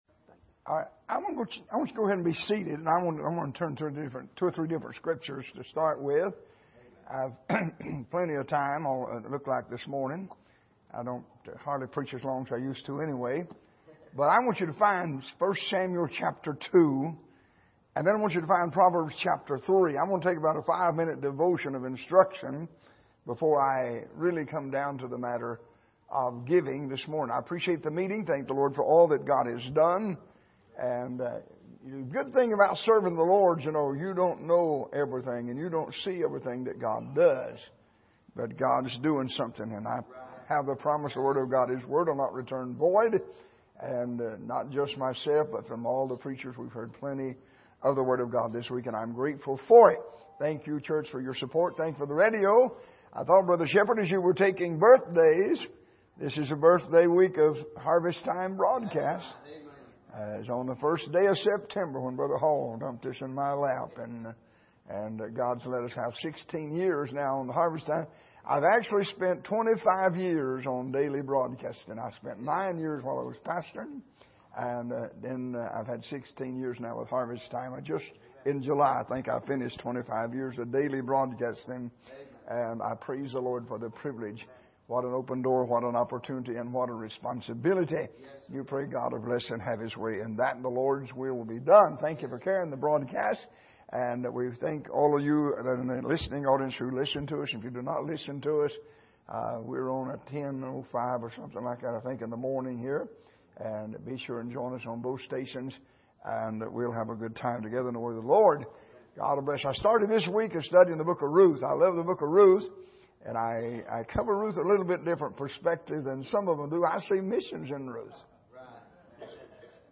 Passage: 1 Corinthians 16:1-4 Service: Missions Conference